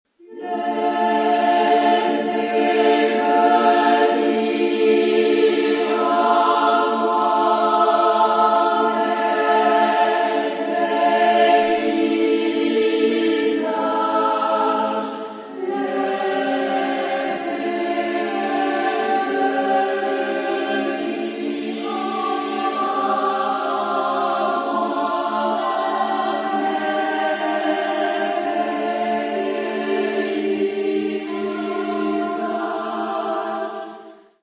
Genre-Style-Form: Secular ; Poem ; Contemporary
Type of Choir: SSAA  (4 women voices )
Soloist(s): Soprano (1) + Alto (1)  (2 soloist(s))
Tonality: polymodal